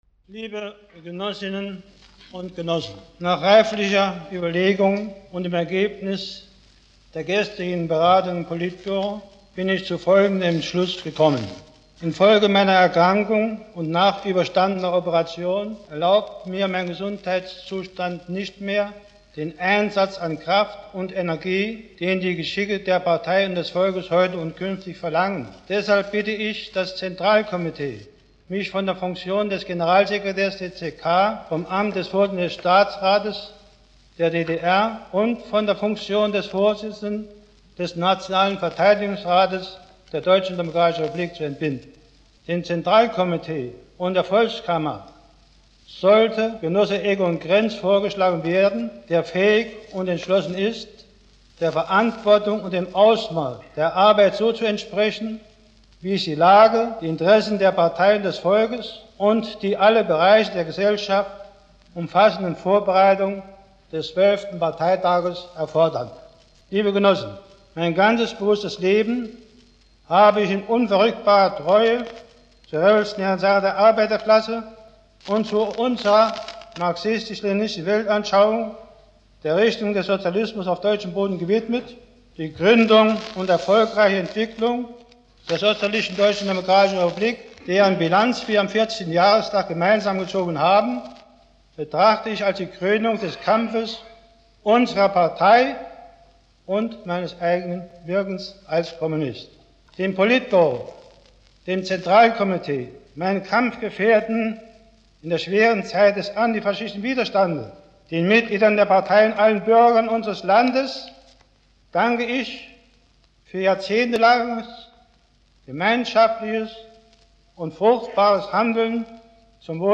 Название: Хонеккер - Прощальная речь - 02:49
Исполняет: Erich Honecker Исполнение 1989г.